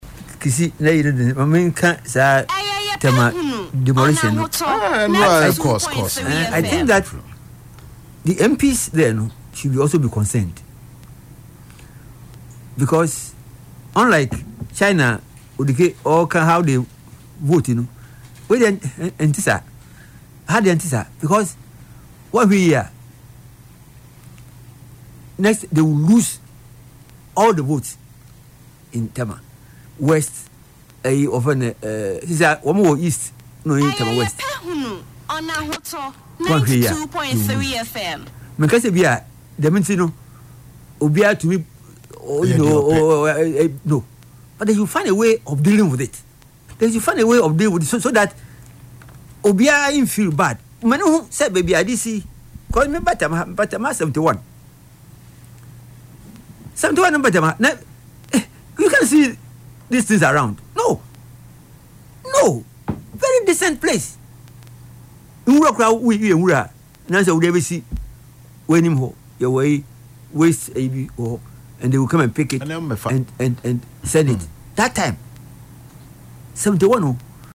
Speaking on Ahotor FM’s “Yepe Ahunu” show on Saturday, November 8